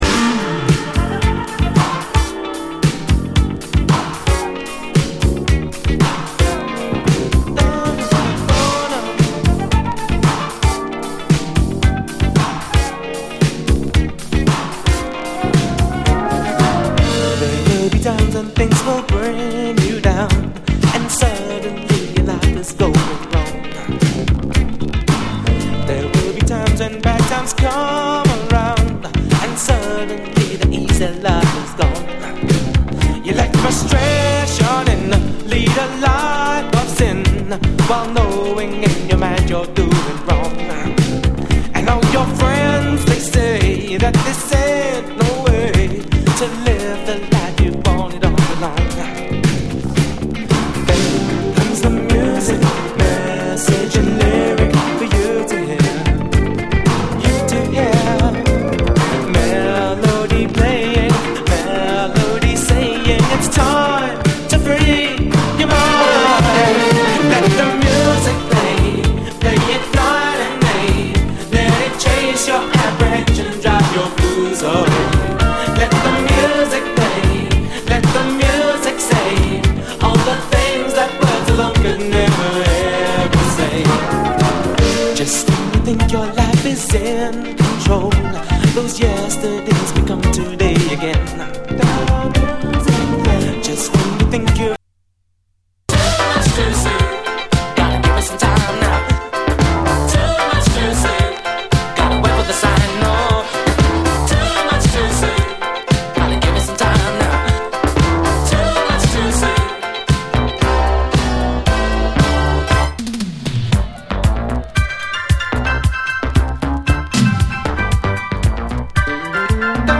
SOUL, 70's～ SOUL, DISCO
ユーロ産80’Sモダン・ソウル！アーバン＆メロウなシンセ・モダン・ソウル
ファンカラティーナ的なノー天気なトロピカル・ソウル